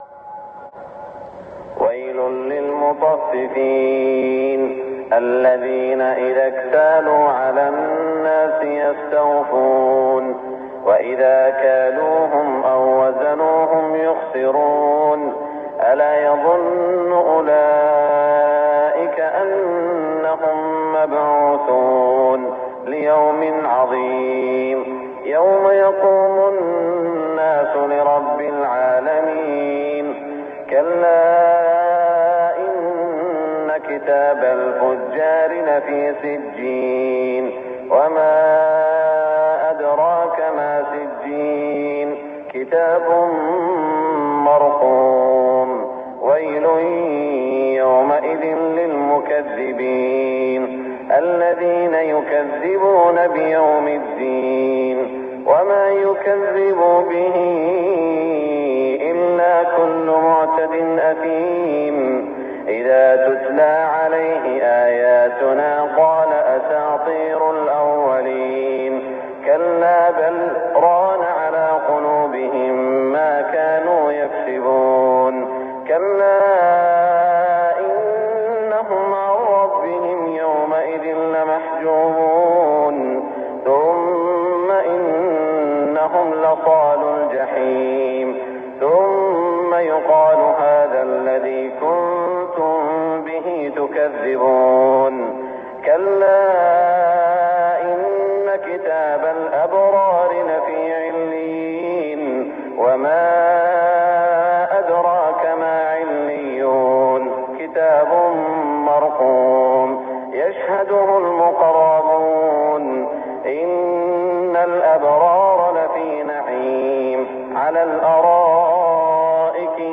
صلاة الفجر 1420 من سورة المطففين > 1420 🕋 > الفروض - تلاوات الحرمين